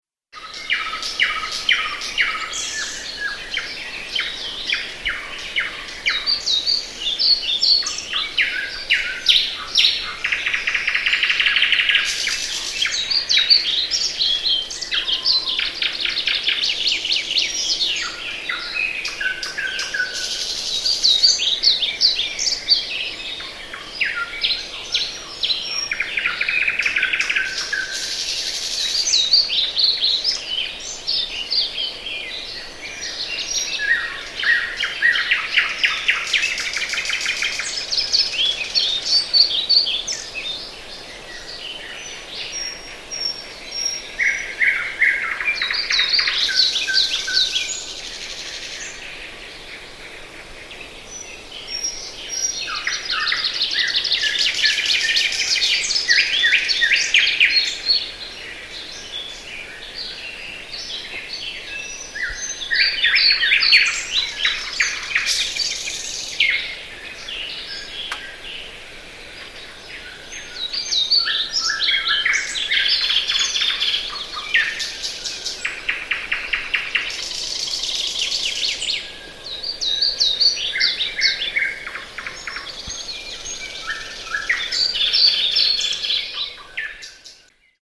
Some more nightingales
I recorded this in 1998 near Pori, Finland.